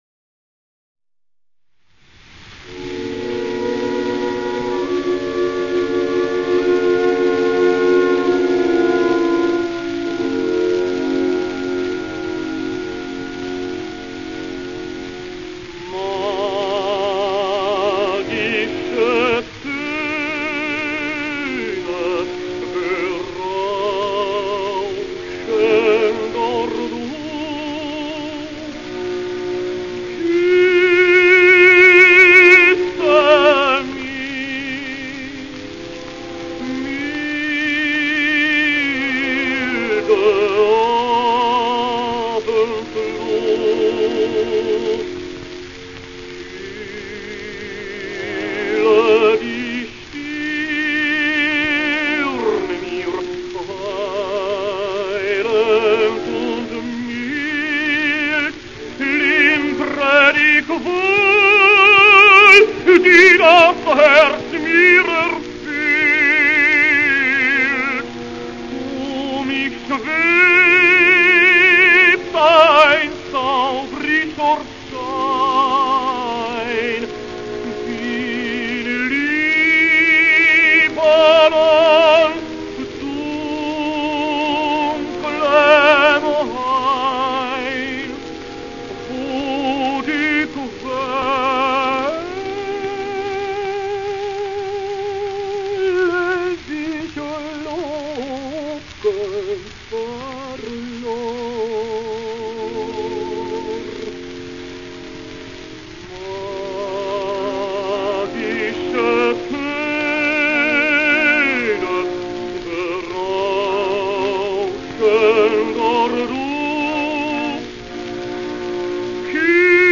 Austrian-Czech tenor, 1873 - 1946
with orchestra